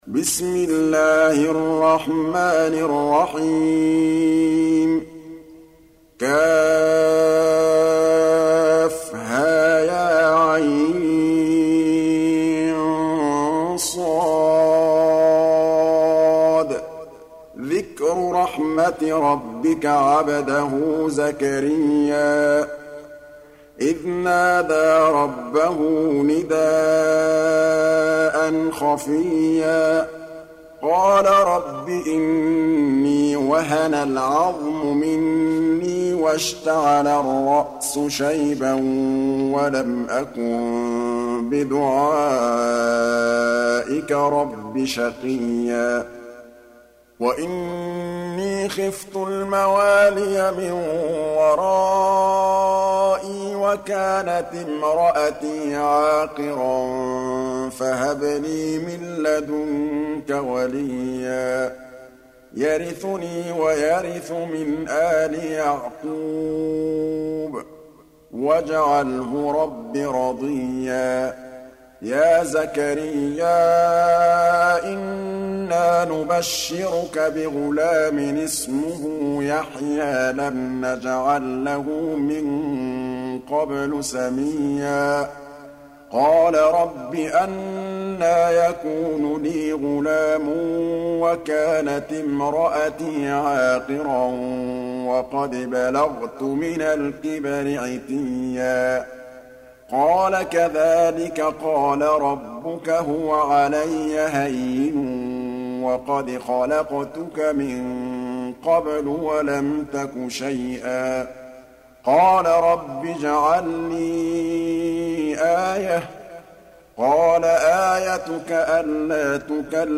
19. Surah Maryam سورة مريم Audio Quran Tarteel Recitation
Surah Repeating تكرار السورة Download Surah حمّل السورة Reciting Murattalah Audio for 19.